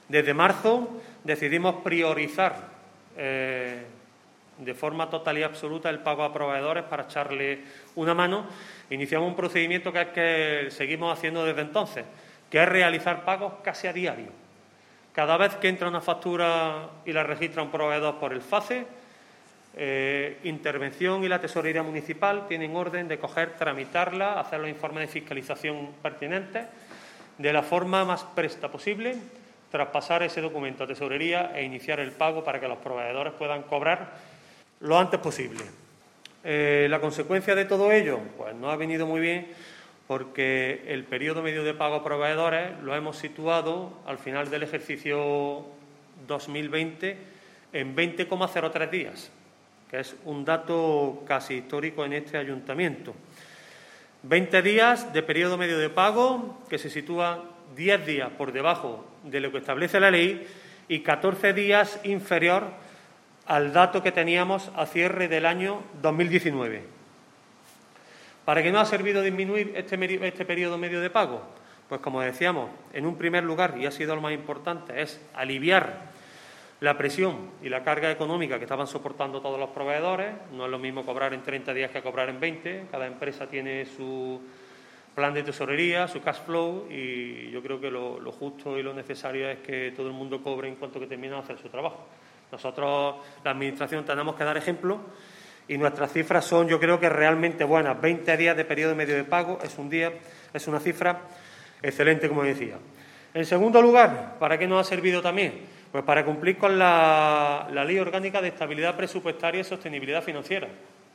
El teniente de alcalde delegado de Hacienda, Antonio García, ha comparecido en la mañana de hoy ante los medios de comunicación para resumir la actividad económica del Ayuntamiento de Antequera durante el pasado año 2020 en lo que a pagos se refiere.
Cortes de voz